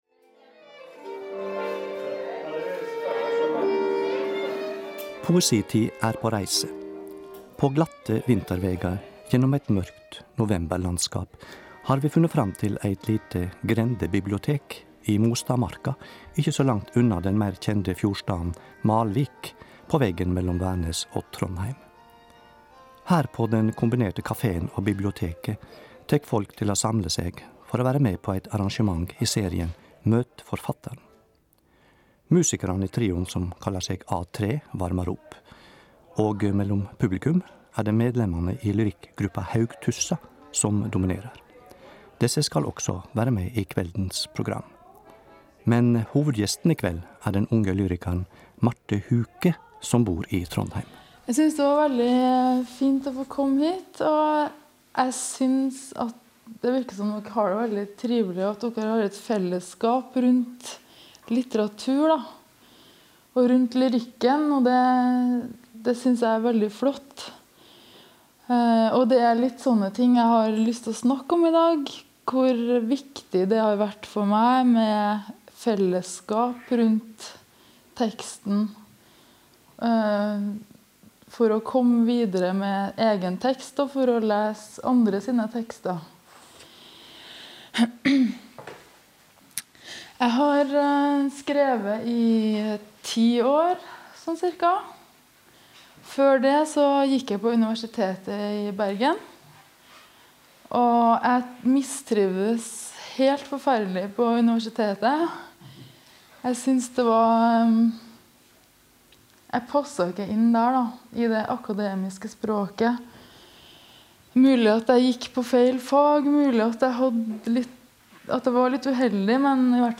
Poesidigg. Podcast.
Lesninger og samtale om forfatterskapet.